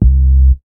MoogSubDown A.WAV